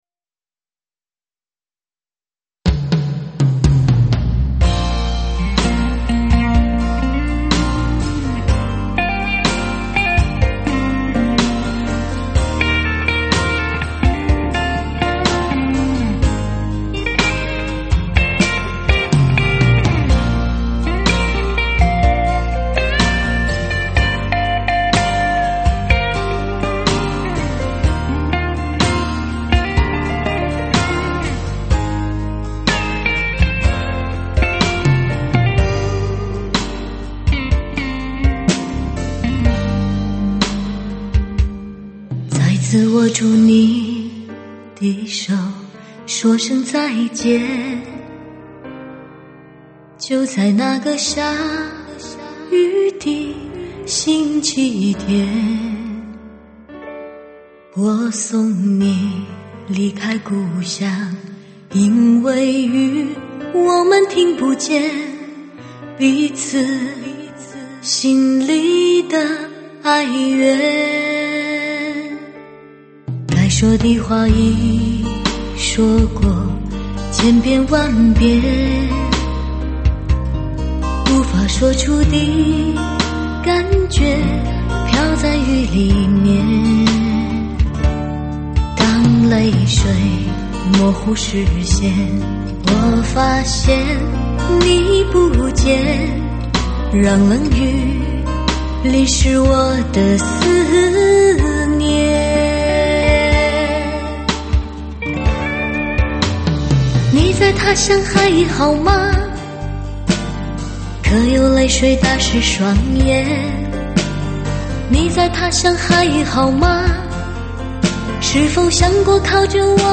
HI-FI发烧高保真新民歌真情系列CRTF立体声录音制式